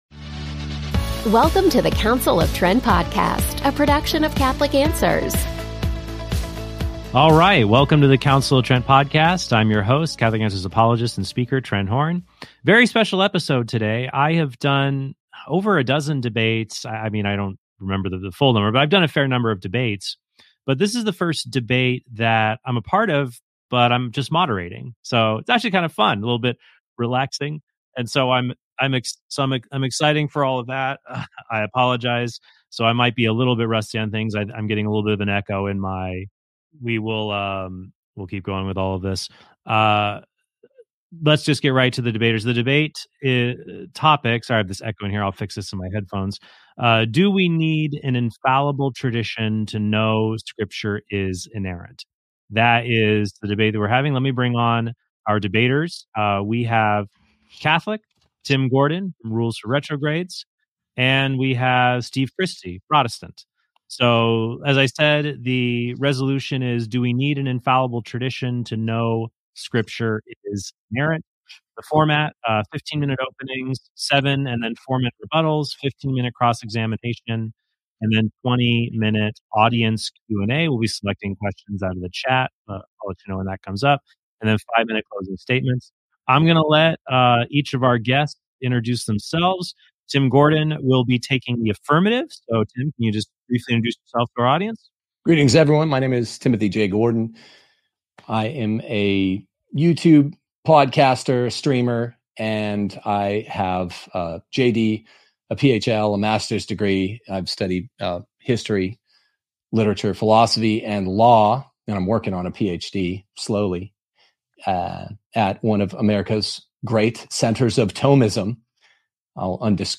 DEBATE: Does Inerrant Scripture Need an Infallible Tradition?
Format: 15-minute opening statements 7-minute rebuttals 4-minute second rebuttals 15-minute cross examinations 20-minute audience Q+A 5-minute closing statements Transcript